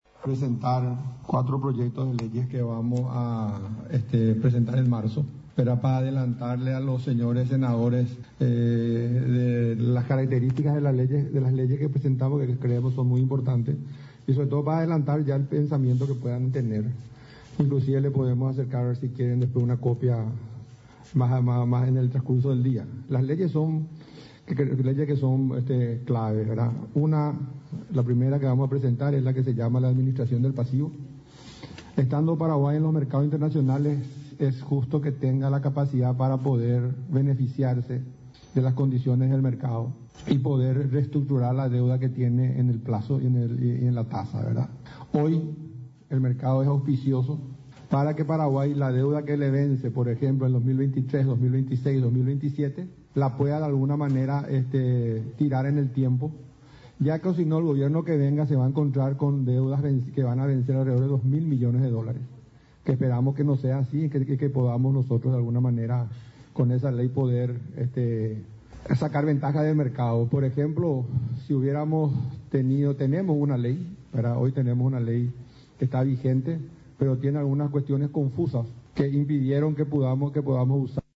Habló en la ocasión el ministro de Hacienda, Benigno López, quien se reunió con legisladores para el debate.